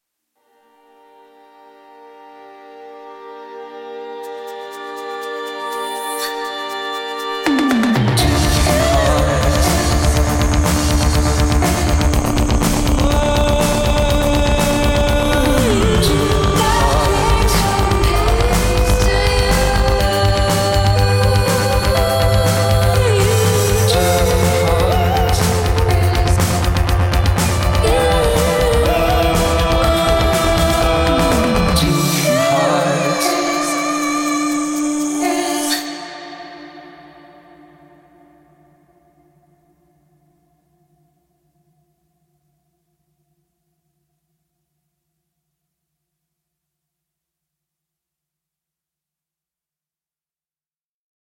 Musikauszug